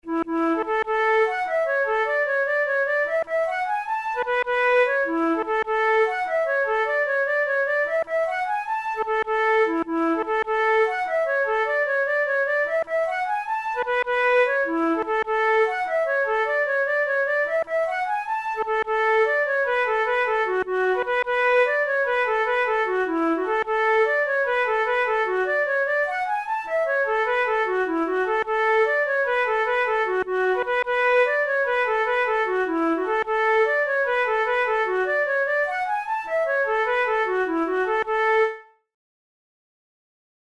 InstrumentationFlute solo
KeyA major
Time signature6/8
Tempo100 BPM
Jigs, Traditional/Folk
Traditional Irish jig